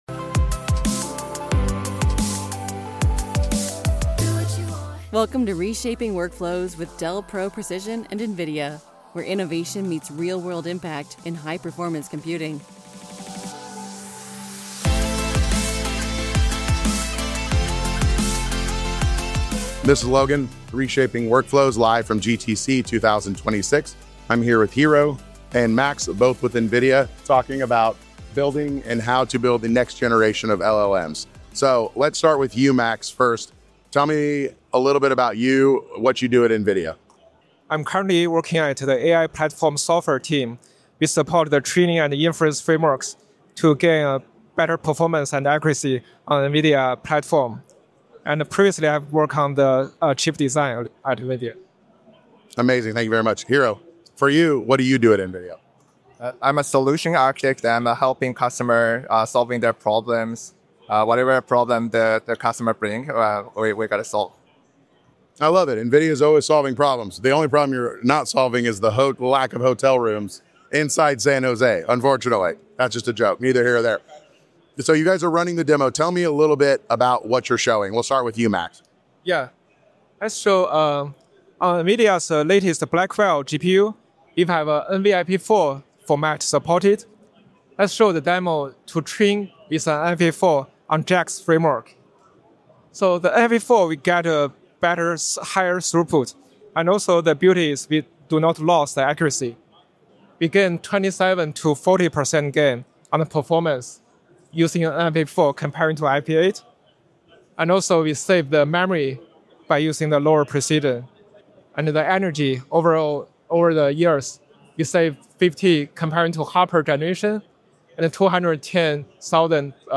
Live from GTC: Train Smarter, Not Bigger with NVFP4